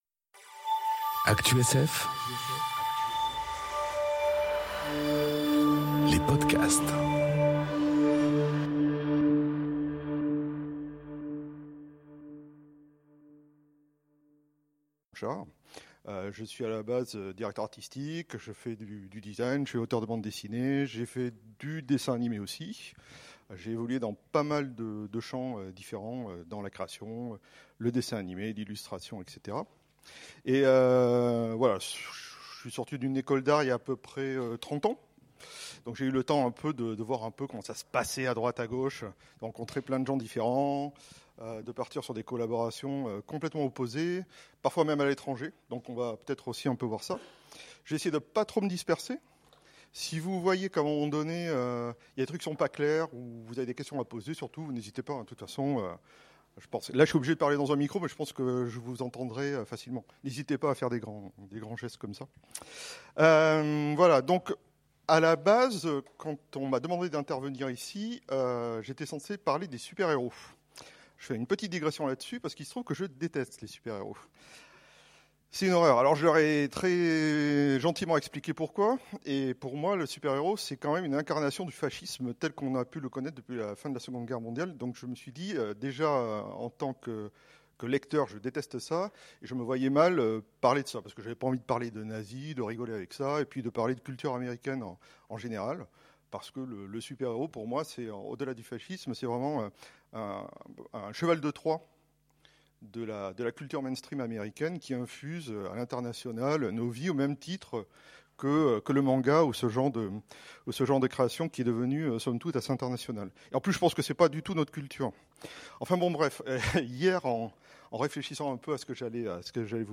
Conférence Le corps de l'extraterrestre dans la BD enregistrée aux Utopiales